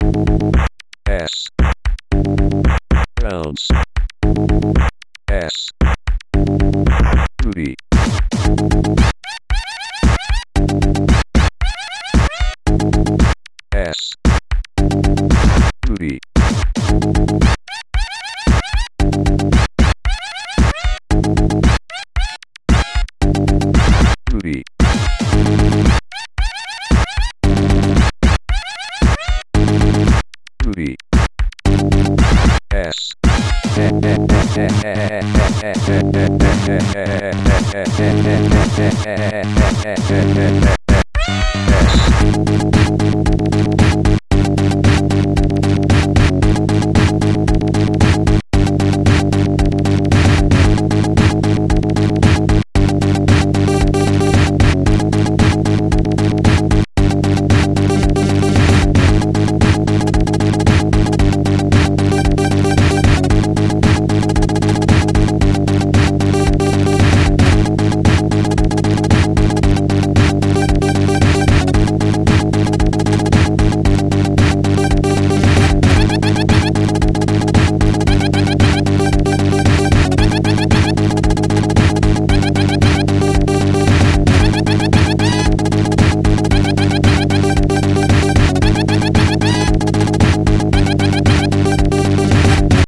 sympa le morceau booty bASS...allez je vais m'y mettre